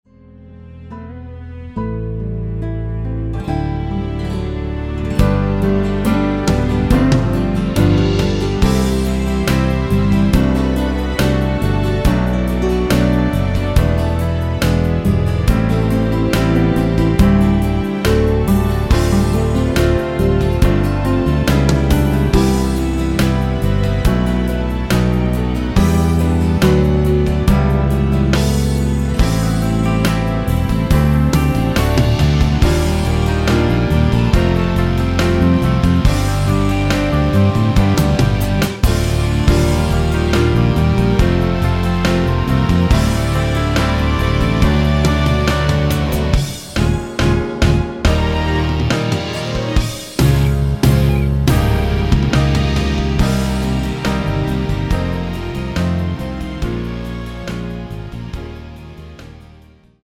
원키에서(-1)내린 짧은편곡 MR입니다.
앞부분30초, 뒷부분30초씩 편집해서 올려 드리고 있습니다.
중간에 음이 끈어지고 다시 나오는 이유는